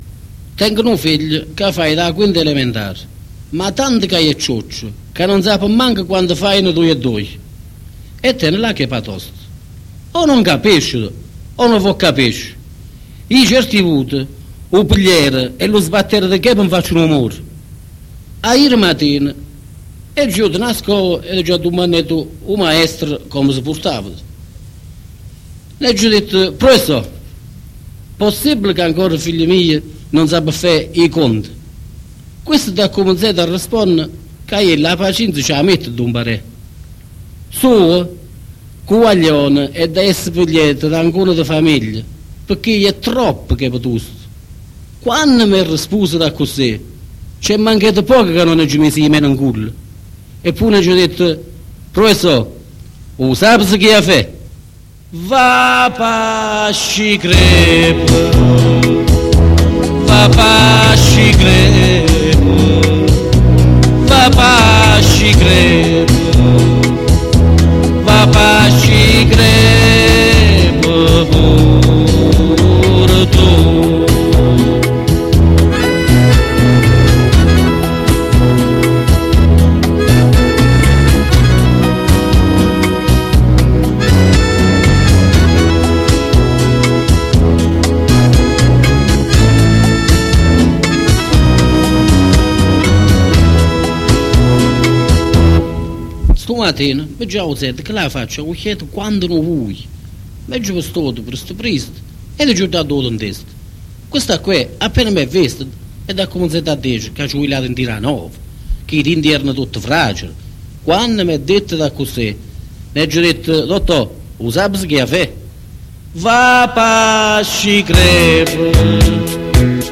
in dialetto cannese